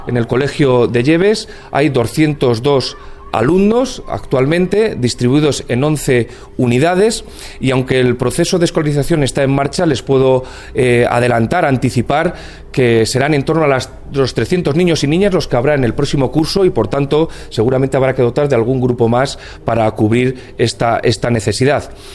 El delegado de la Junta en Guadalajara, Alberto Rojo, habla del aumento del alumnado del colegio de Yebes de cara al próximo curso escolar.